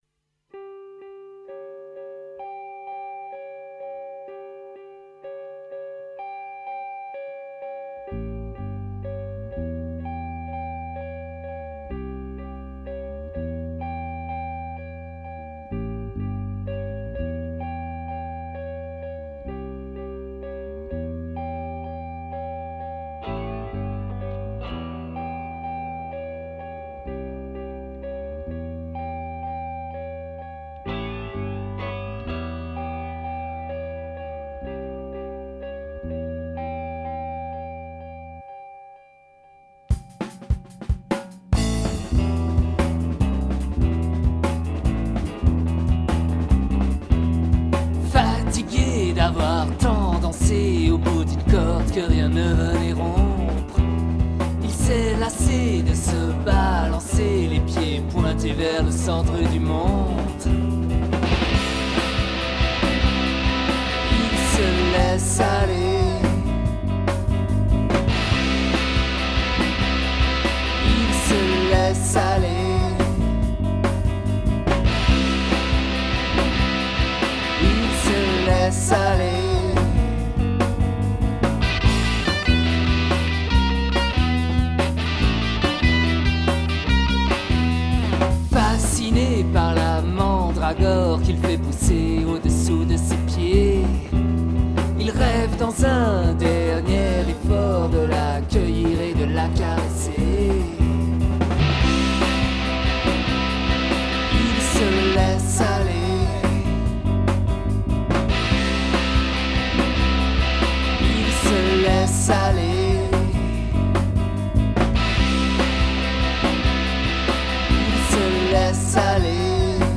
voix, guitare
basse
batterie